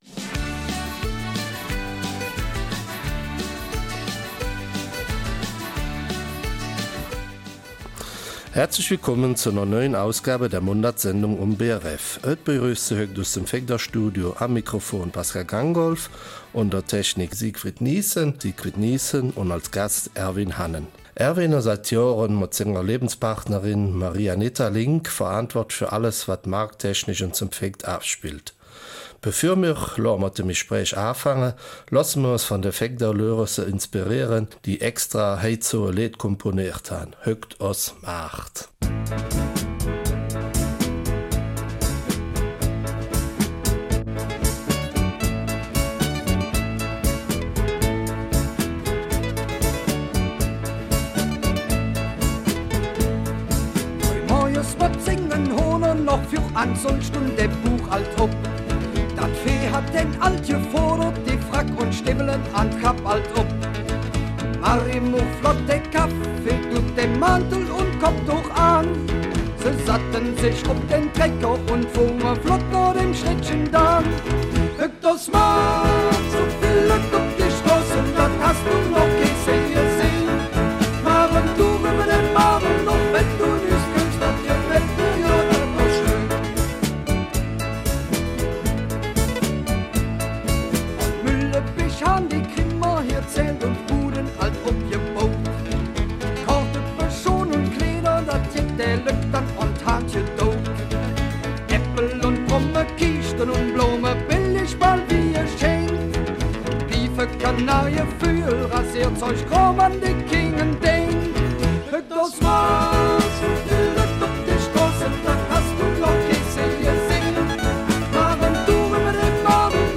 Eifeler Mundart: Marktstandort St.Vith